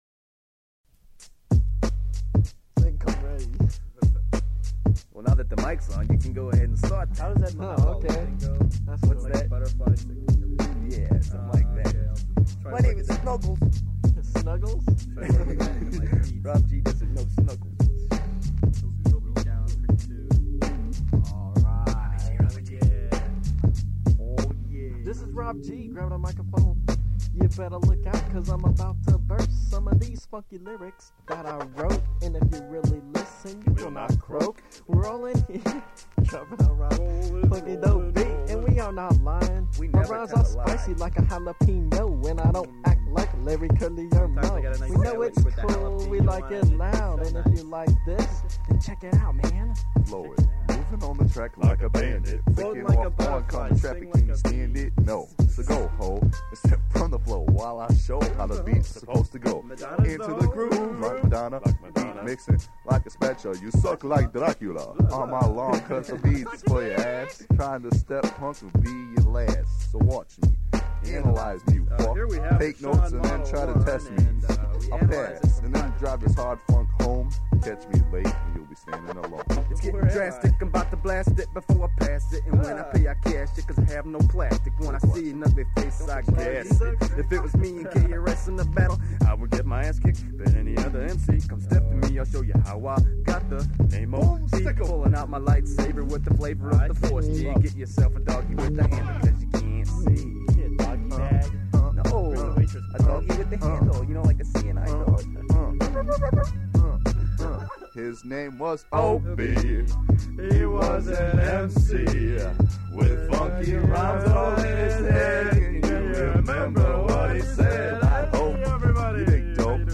posse cut